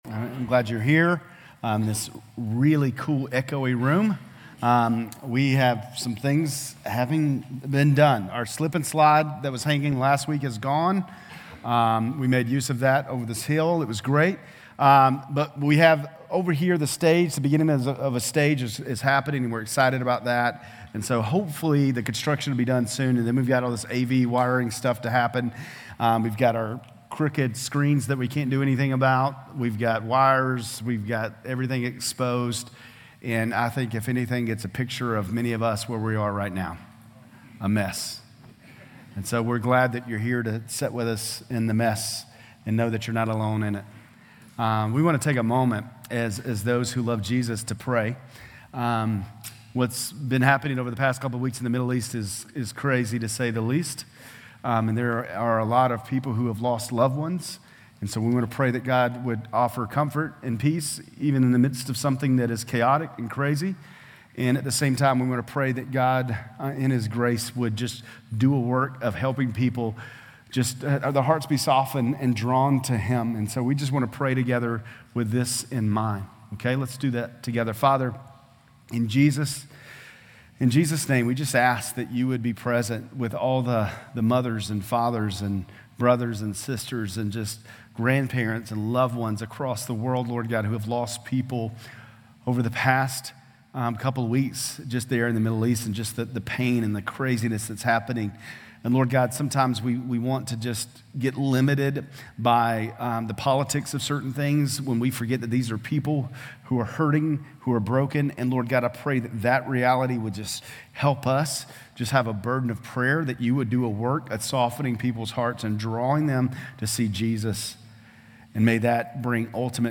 Grace Community Church Lindale Campus Sermons Crucial Conversations: Acts 10 Oct 15 2023 | 00:30:45 1x 00:00 / 00:30:45 Subscribe Share RSS Feed Share Link Embed